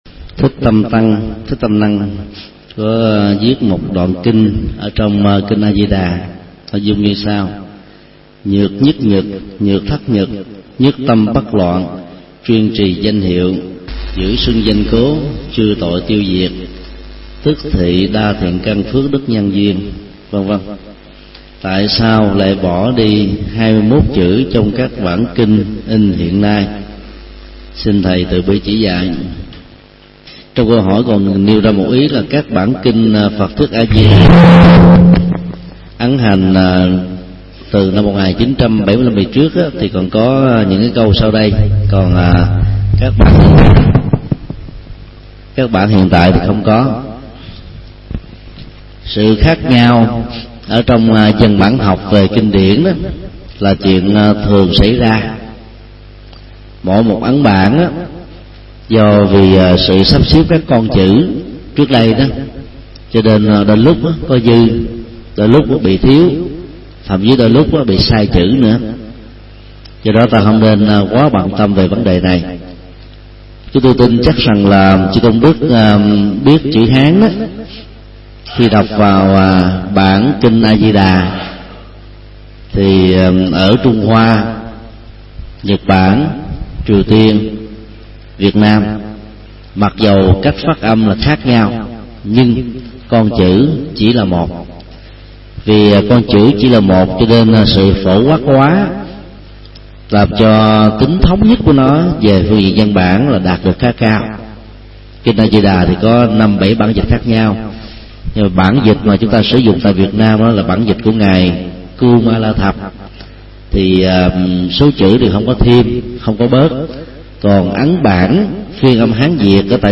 Vấn đáp: Bản dịch, ấn bản Kinh A Di Đà